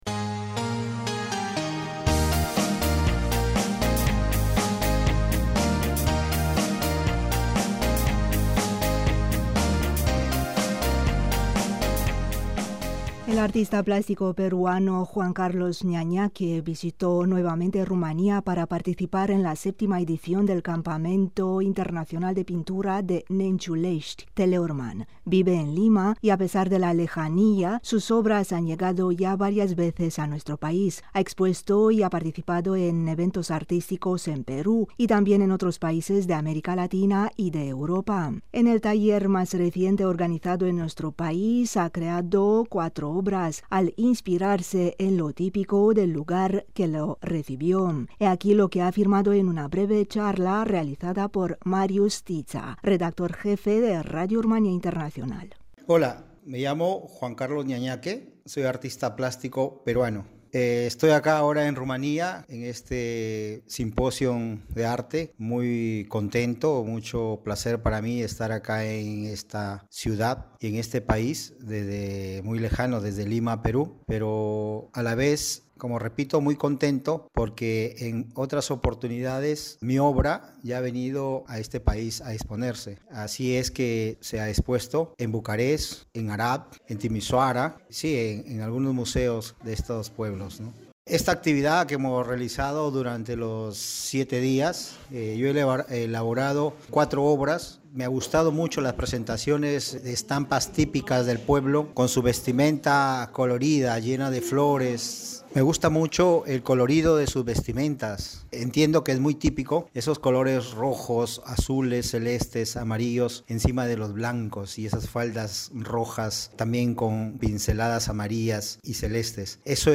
He aquí lo que afirmó en una breve charla